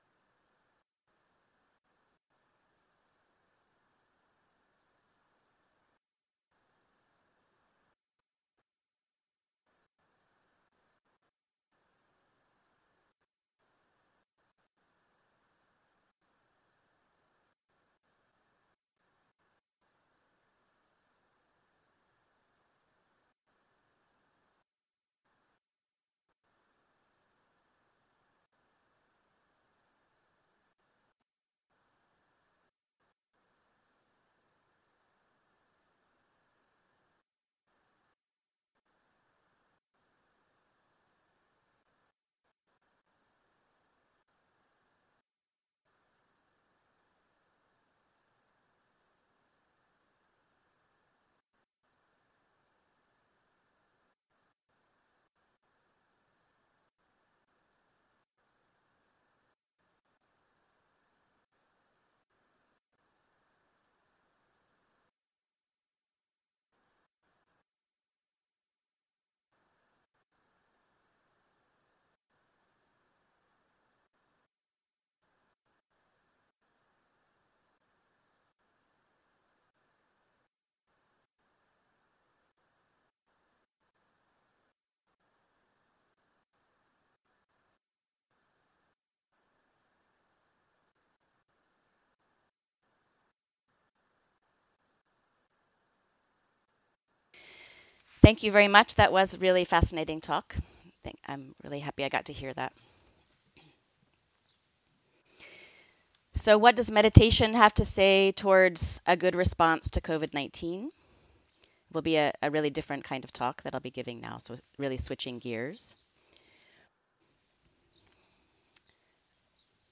Guided meditations and meditation instructions broadcast from around the world